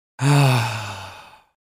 男人叹气声音效_人物音效音效配乐_免费素材下载_提案神器